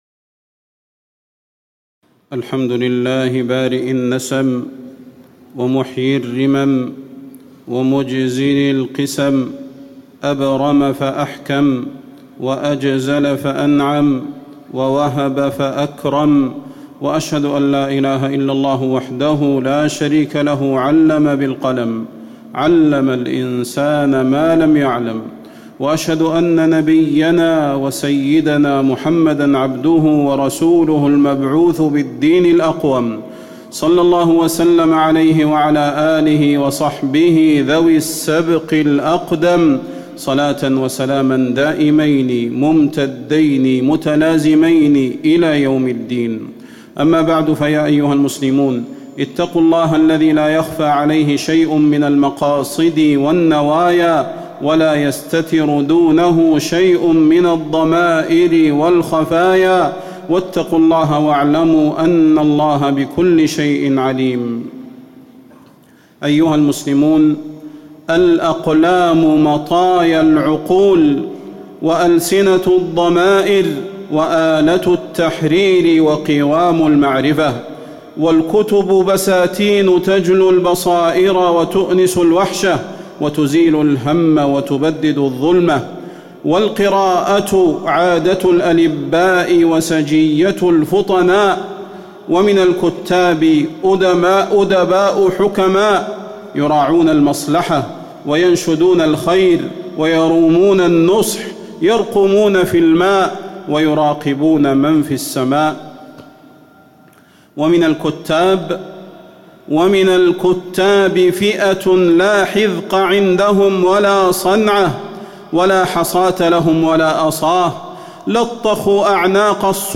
فضيلة الشيخ د. صلاح بن محمد البدير
تاريخ النشر ١٨ صفر ١٤٣٨ هـ المكان: المسجد النبوي الشيخ: فضيلة الشيخ د. صلاح بن محمد البدير فضيلة الشيخ د. صلاح بن محمد البدير توجيهات الى القراء والكتاب